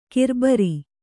♪ kirbari